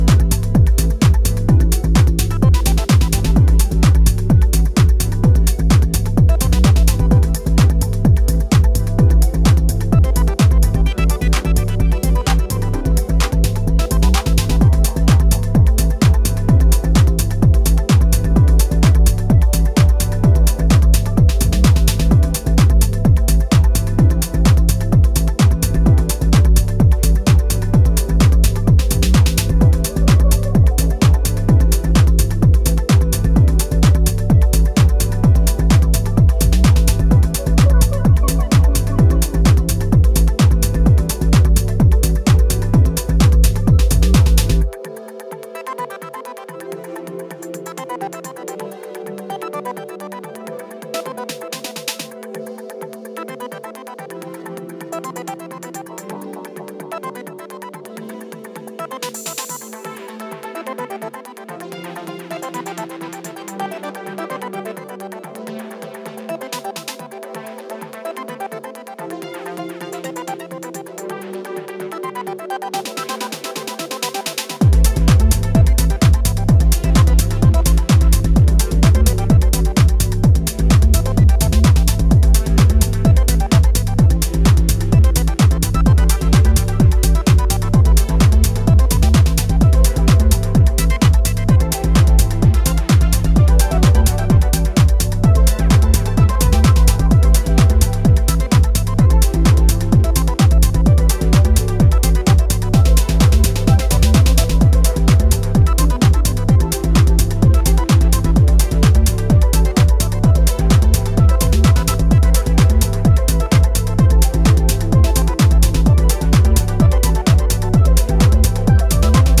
highly energetic, spacey and vibrant sound